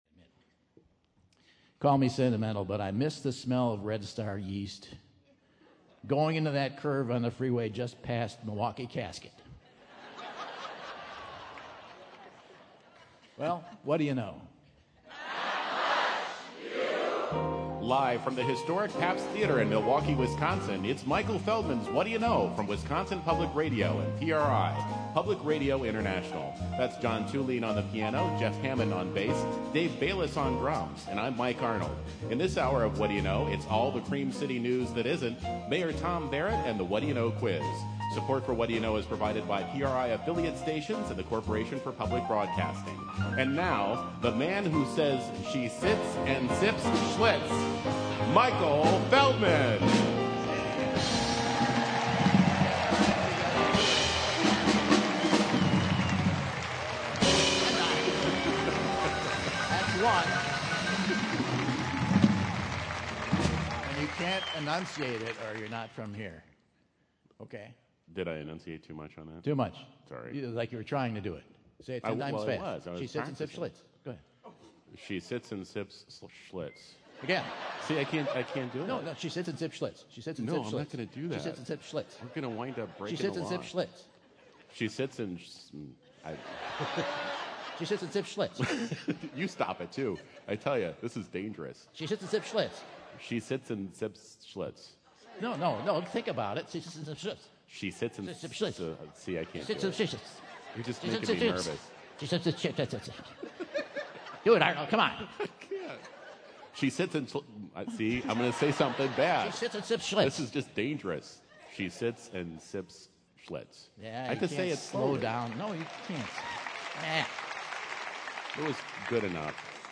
While at the Historic Pabst Theater in Milwaukee and revises current headlines to his lens prescription!